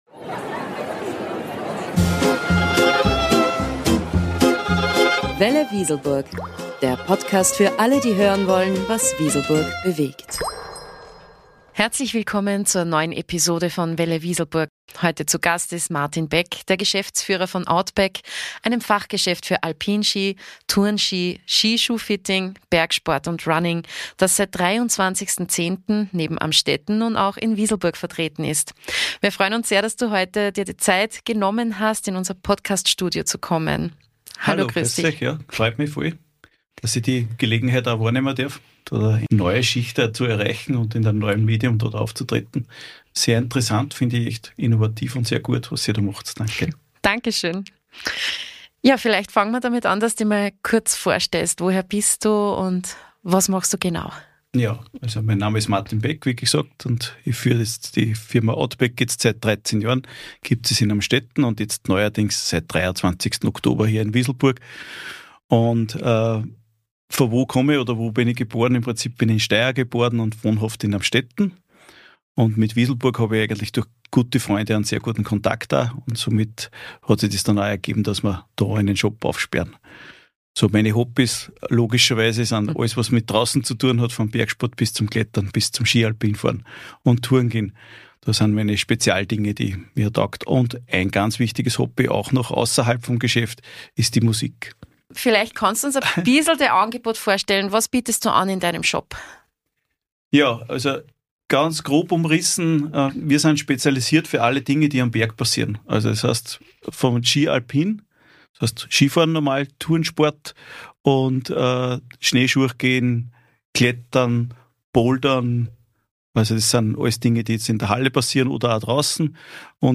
Folge 32 | Faszination Outdoor Sport, ein Gespräch